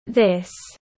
• Pour le « th » prononcé de manière légère :